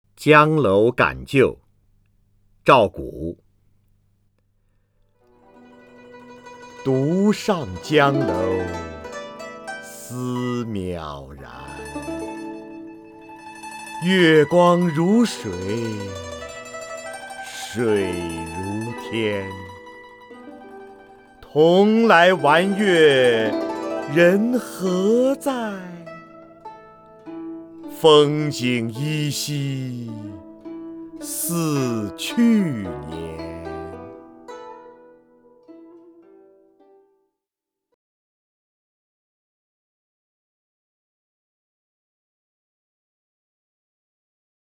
瞿弦和朗诵：《江楼感旧》(（唐）赵嘏) （唐）赵嘏 名家朗诵欣赏瞿弦和 语文PLUS